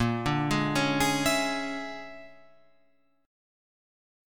A#7#9b5 Chord
Listen to A#7#9b5 strummed